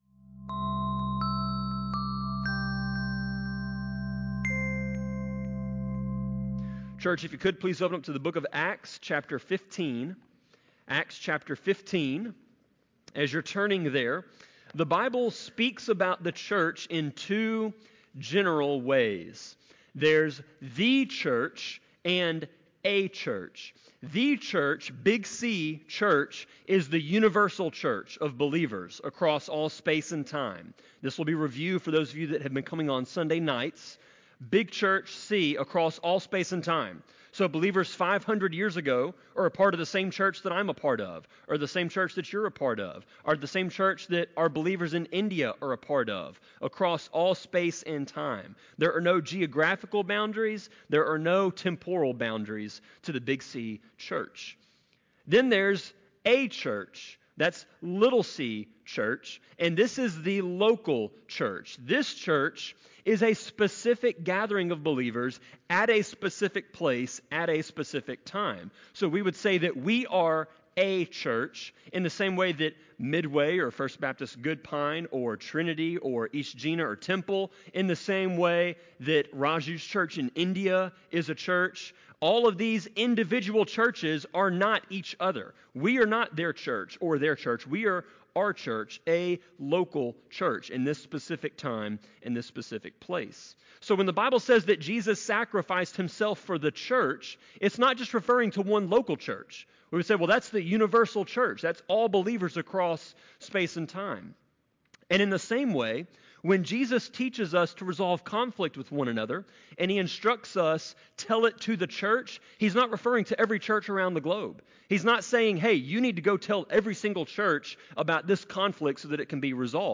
Sermon-24.7.7-CD.mp3